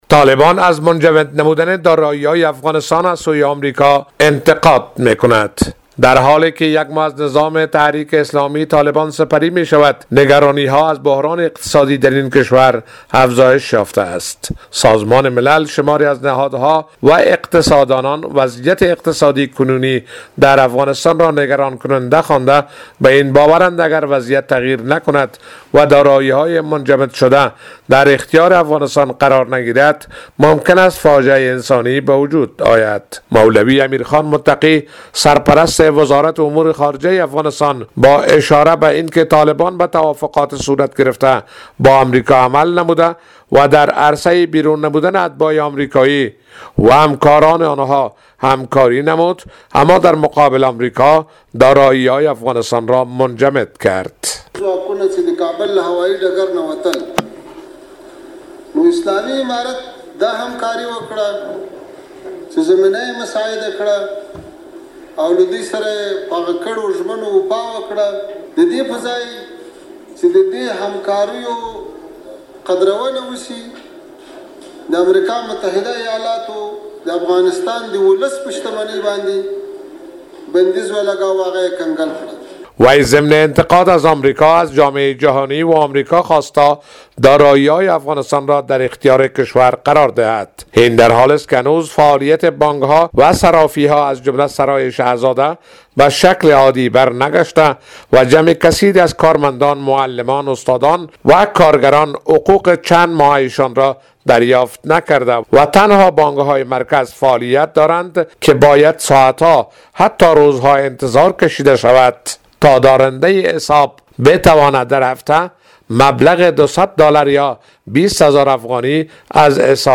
گزارش تکمیلی